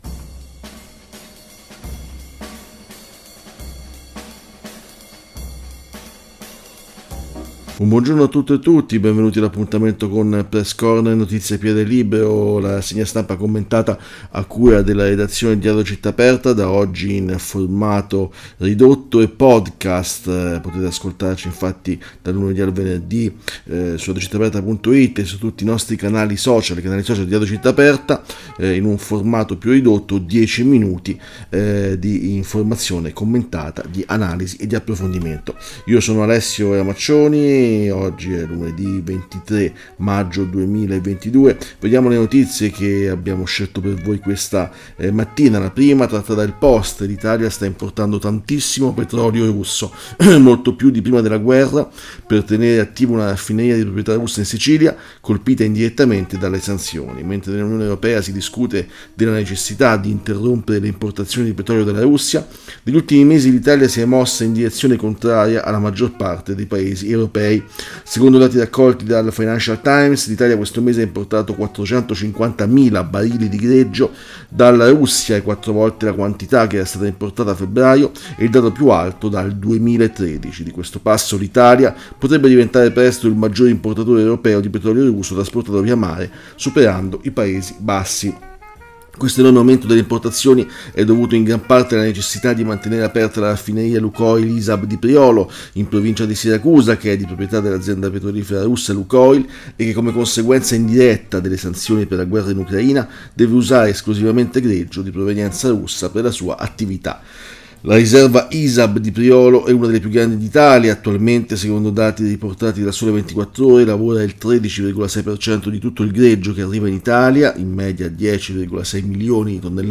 Leggiamo e commentiamo insieme articoli di approfondimento apparsi su quotidiani, periodici, blog, siti specializzati su tutti i temi di interesse del momento.